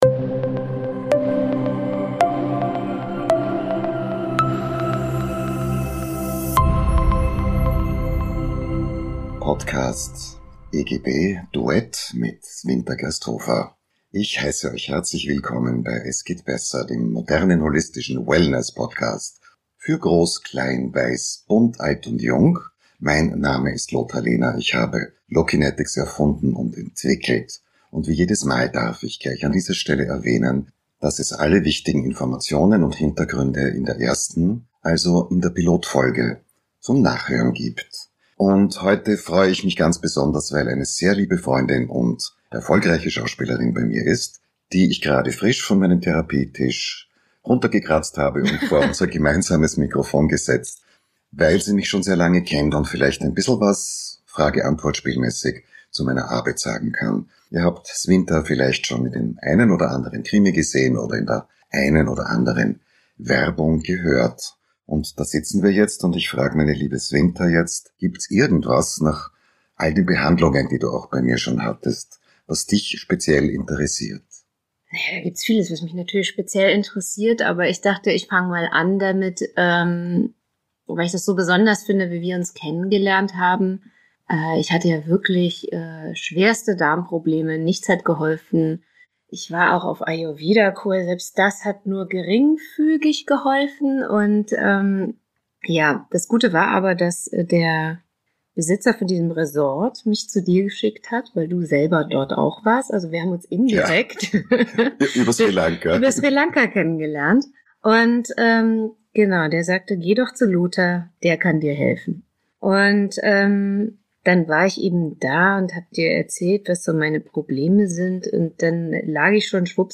Ein einfühlsames Gespräch über körperliche Beschwerden, unverarbeitete Themen und die Bedeutung ganzheitlicher Sichtweisen. Im Mittelpunkt steht das Zusammenspiel von Körper und Seele – und warum auch das Sprechen ein wesentlicher Teil des Heilungsprozesses ist.